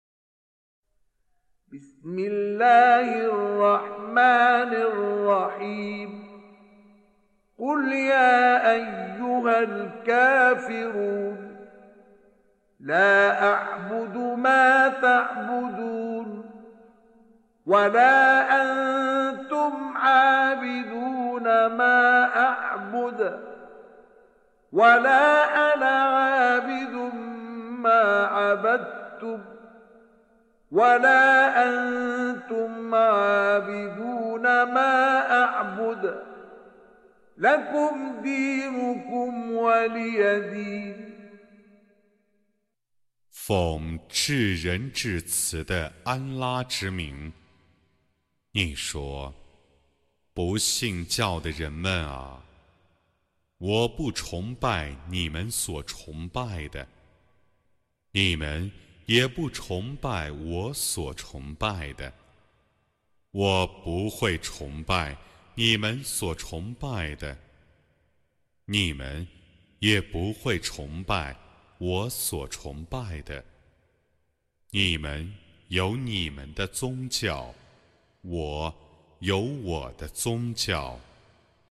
Reciting Mutarjamah Translation Audio for 109. Surah Al-Kâfirûn سورة الكافرون N.B *Surah Includes Al-Basmalah